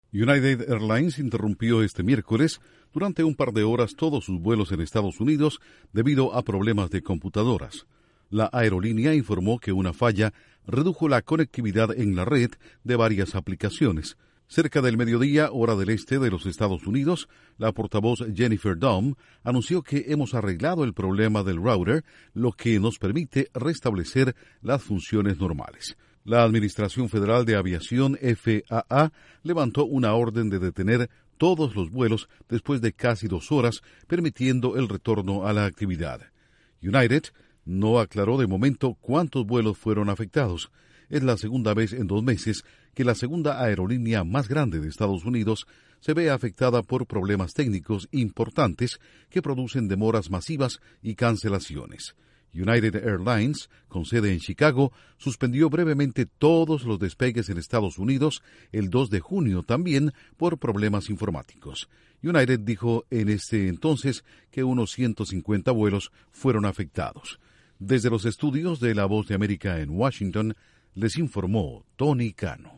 United Airlines reanuda vuelos nacionales e internacionales tras superar una falla informática que redujo la conectividad en su red. Informa desde los estudios de la Voz de América en Washington